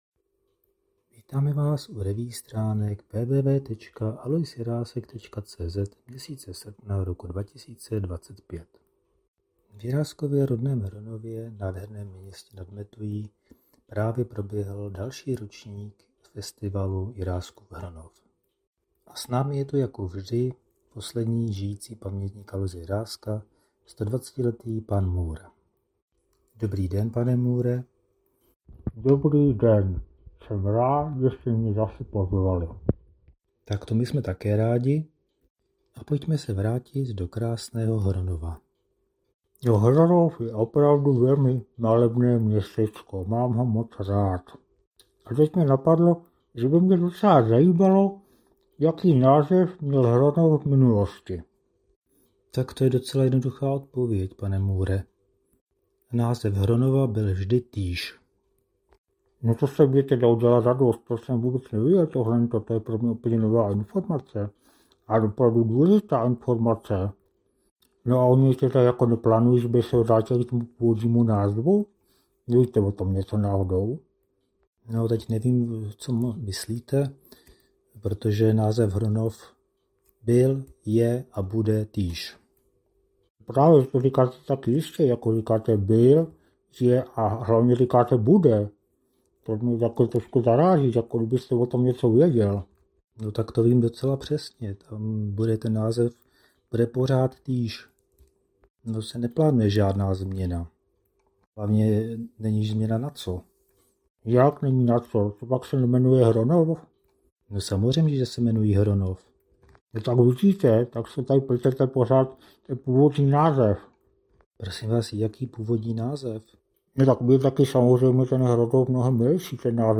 Další amatérský pokus o zvukovou revue se s obvyklou humornou nadsázkou věnuje Jiráskovu rodnému Hronovu, ve kterém se i tento rok konal proslulý festival “Jiráskův Hronov”.